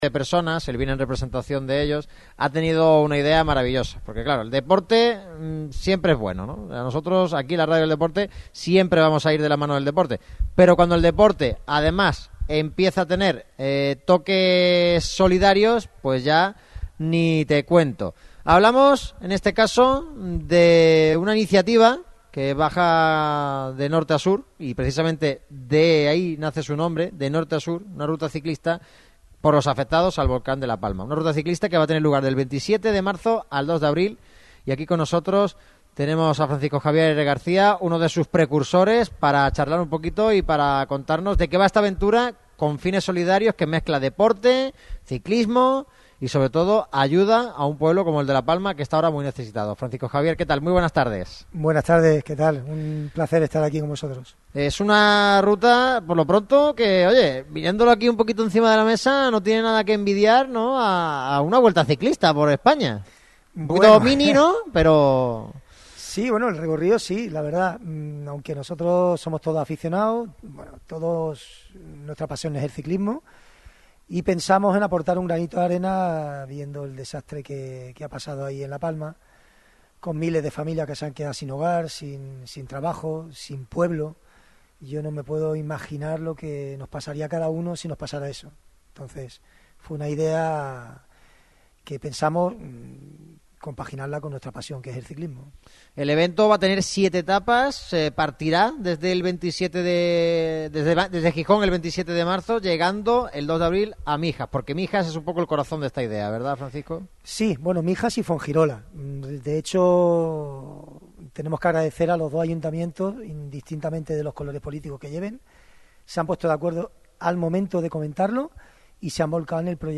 'De Norte a Sur', una ruta solidaria por La Palma - Radio Marca Málaga